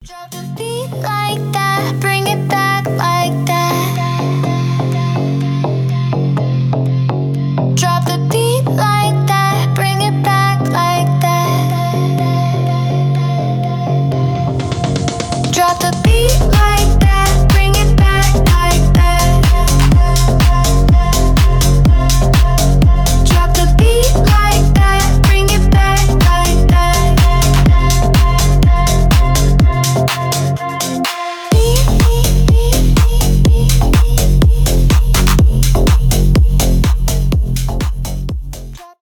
клубные , deep house , bass house
edm